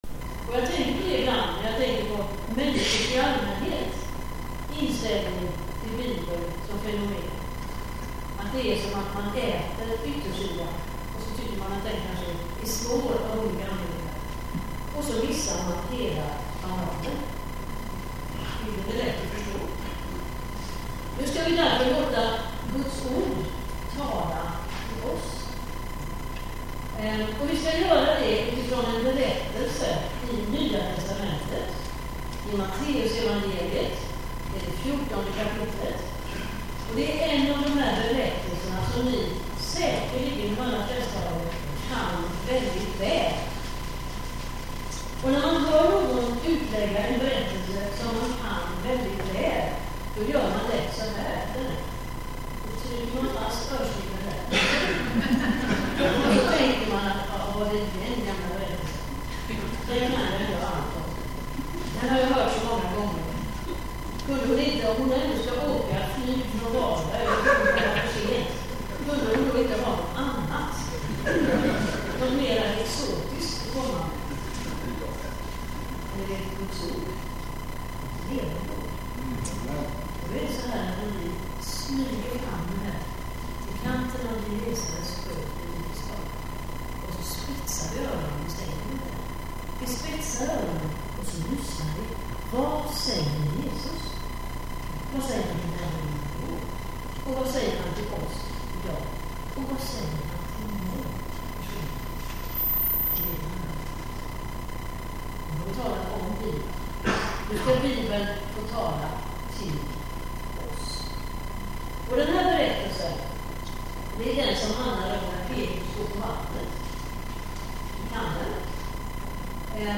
Inspelningar från inspirationshelgen
Tyvärr är ljudkvaliteten lite varierande men gott ändå att kunna påminna sig om allt som undervisades.